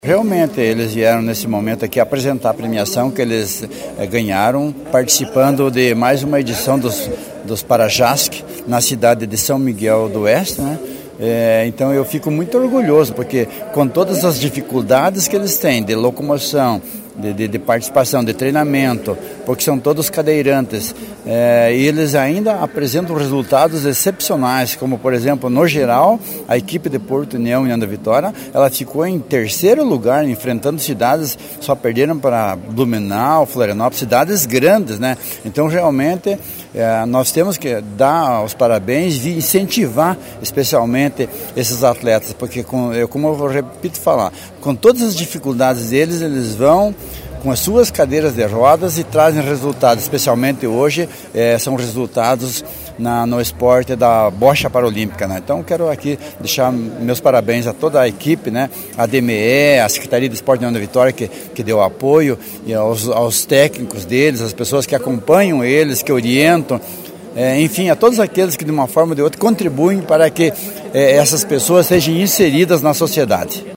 E para confirmar esta valorização, na manhã desta quinta-feira, 14, os atletas realizaram no ginásio de esporte Lauro Muller Soares, a apresentação das medalhas e troféu que conquistaram na competição na cidade de São Miguel do Oeste.
O prefeito de Porto União, Anízio de Souza, recebeu das mãos dos atletas o troféu e a medalha de participação, e destacou a valorização e superação que cada atleta tem em fazer o dia melhor, mesmo com as dificuldades.